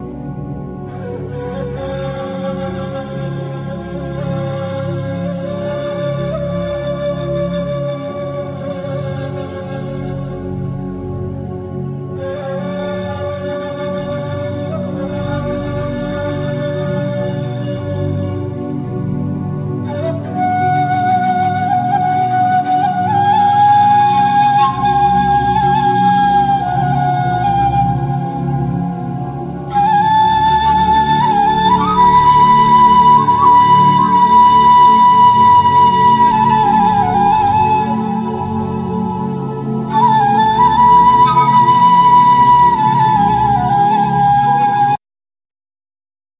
Tar,Riq,Bendir,Shakers,Caxixis,Bodhram
Cajon,Darhuka,Spanish Guitar,Bass Guitar
Viola,Violin